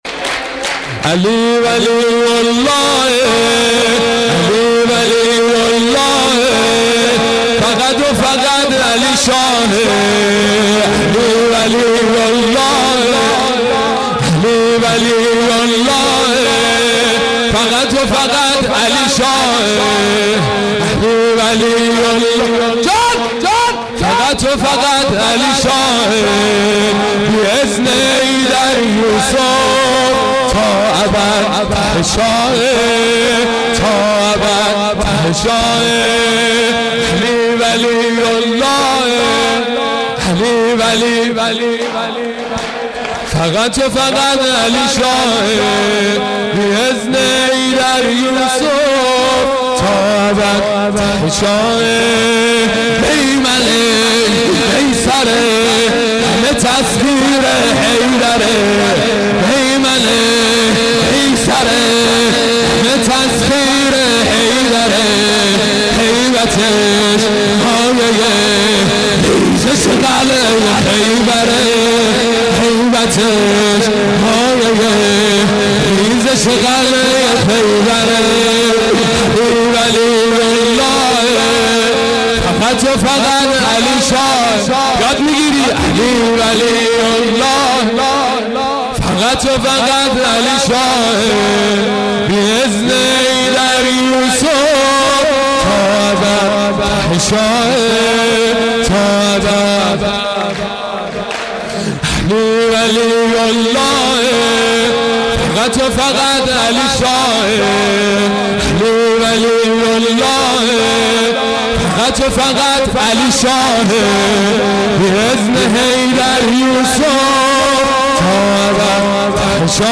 مداحی
هیئت دانشجویی
مولودی
سرود